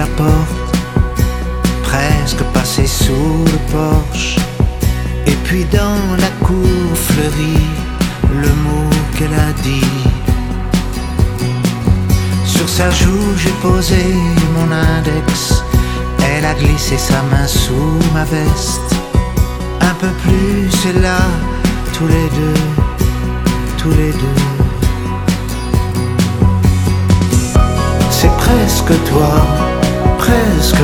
Sujet(s) Chansons de variétés (musique prédominante)